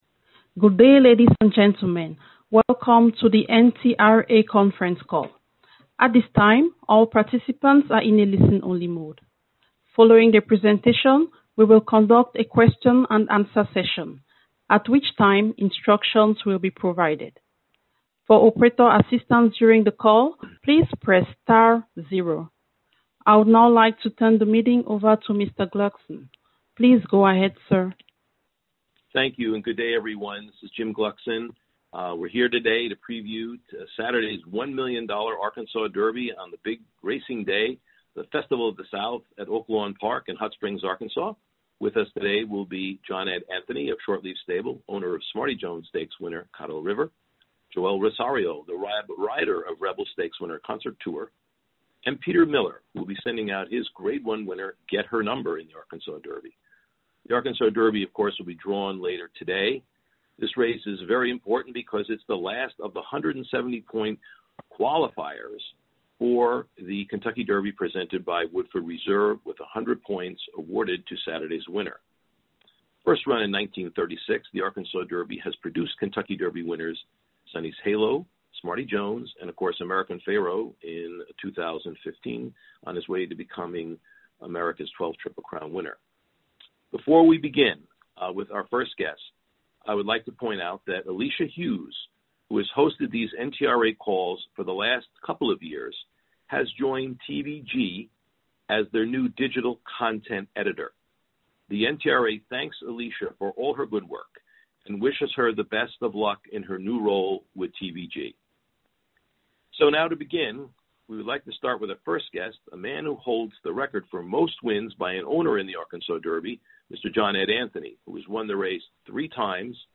Audio of Teleconference: